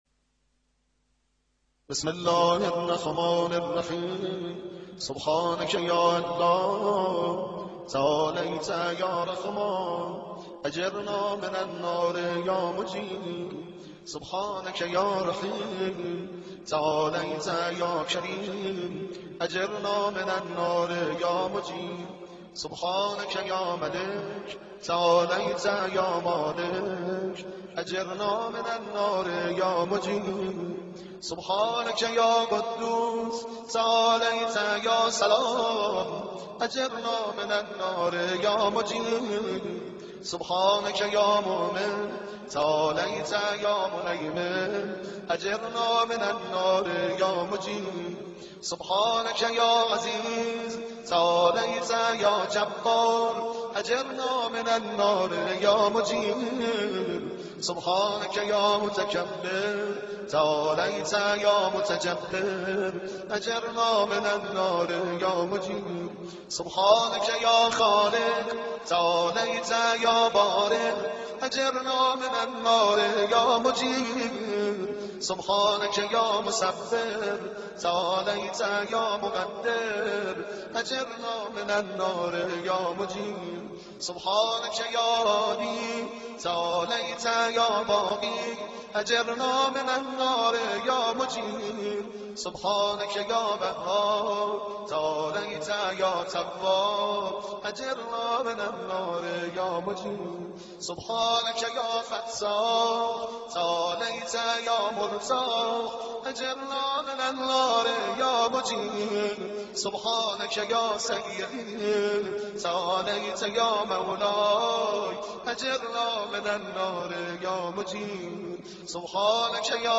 فایل صوتی دعای مجیر تندخوانی